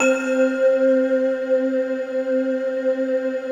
CN3 SYN 2.wav